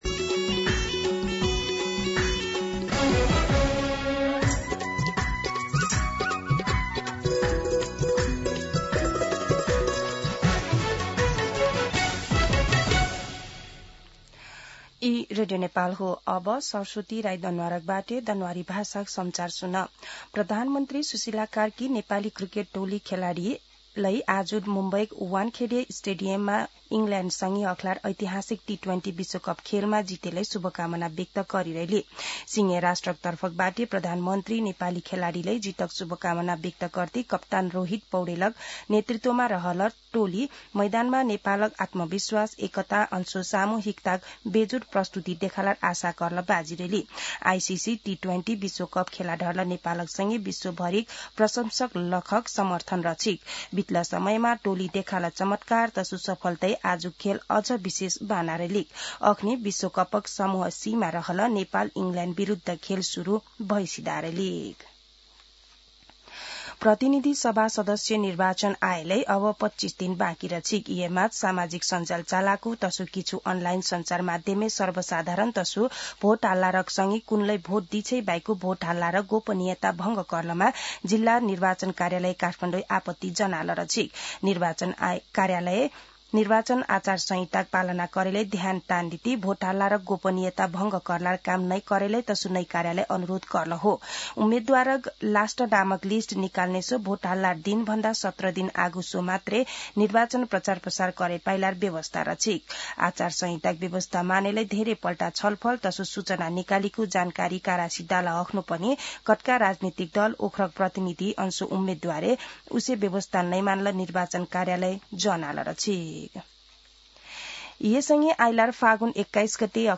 दनुवार भाषामा समाचार : २५ माघ , २०८२
Danuwar-News-25.mp3